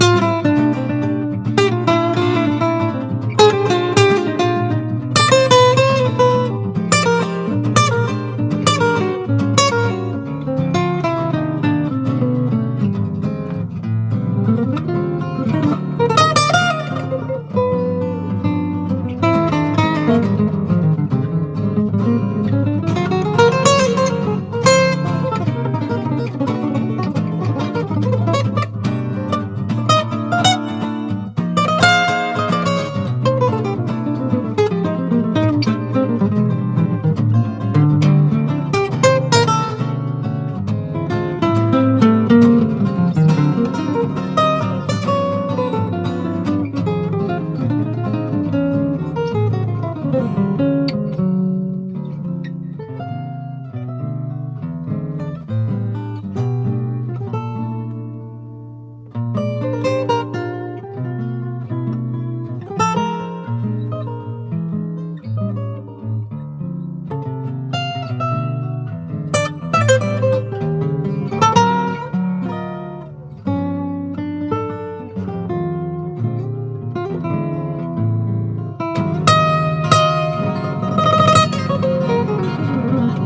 エレクトリック・ジャズ・ファンク/ジャズ・ロックな79年作！
キラメキとファンクネスが混ざり合ったコズミック・フュージョン